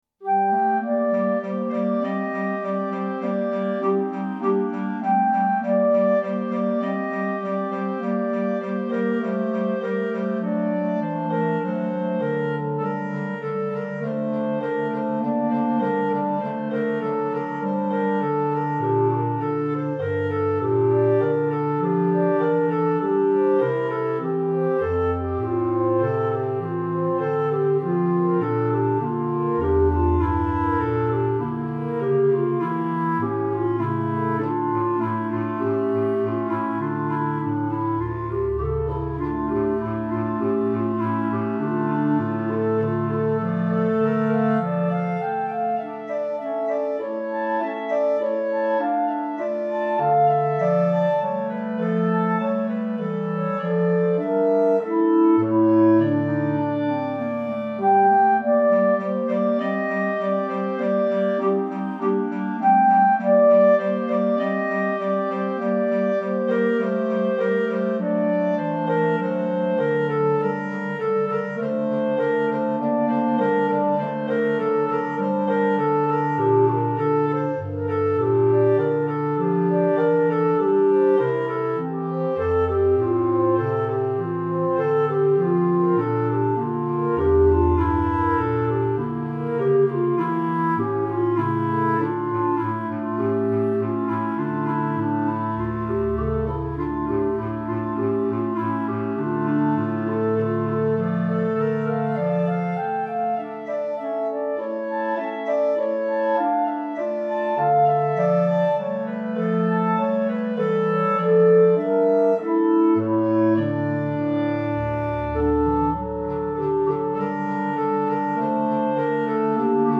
Instrumentation:Clarinet Quartet (includes Bass Cl)